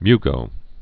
(mygō, m-)